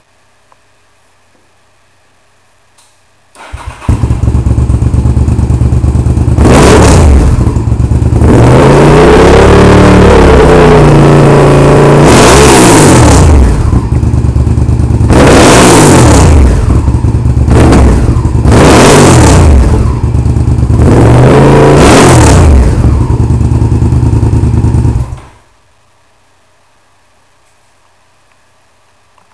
VTR Bafflectomy
VTR-Bafflectomy.wav